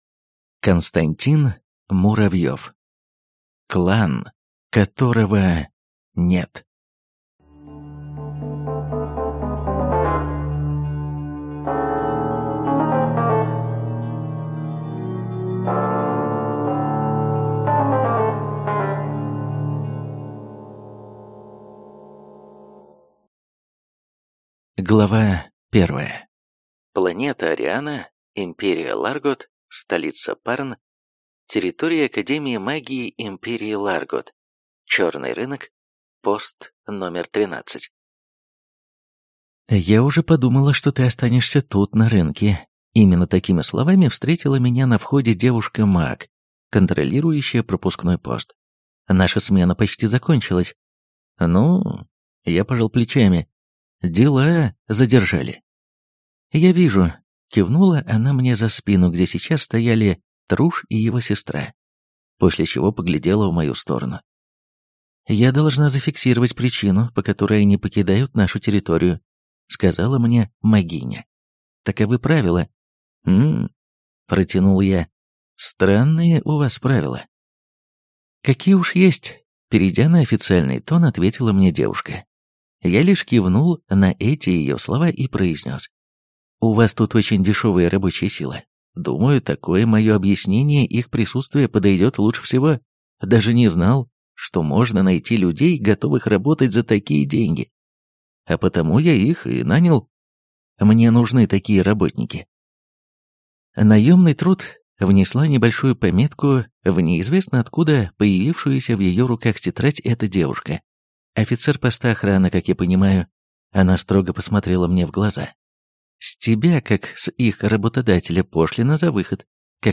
Аудиокнига Пожиратель. Клан, которого нет | Библиотека аудиокниг